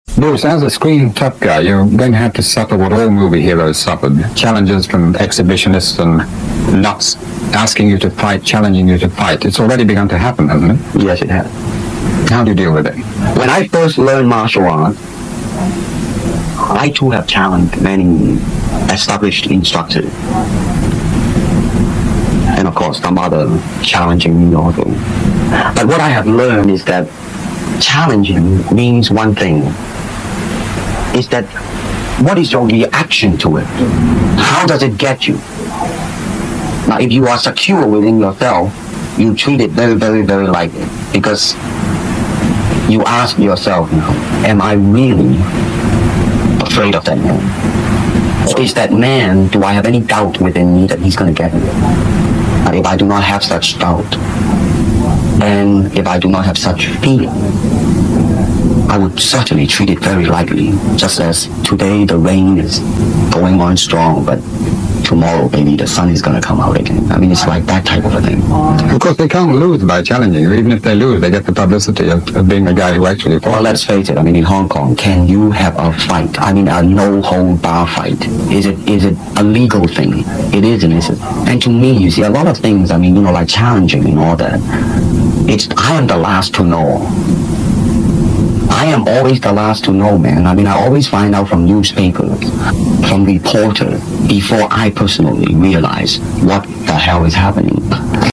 After 2 months of searching, I was able to obtain some of Bruce Lee’s more precious recorded interviews and videos.